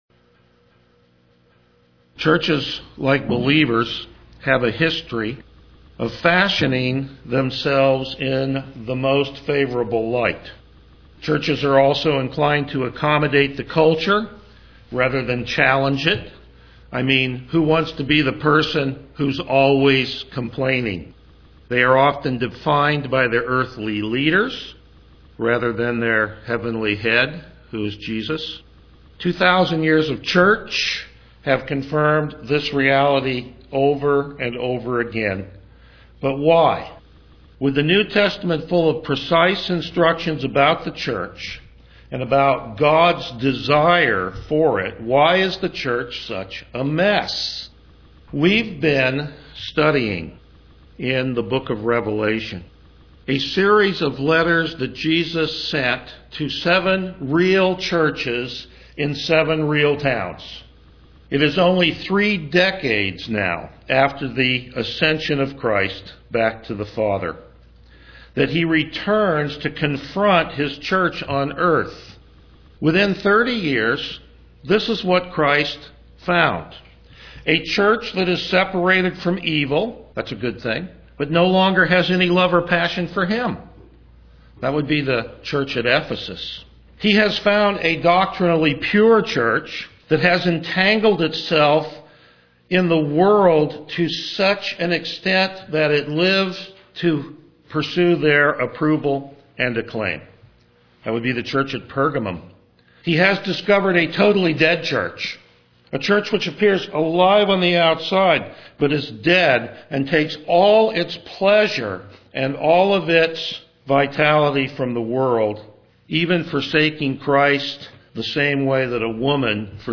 Passage: Revelation 3:7-13 Service Type: Morning Worship
Verse By Verse Exposition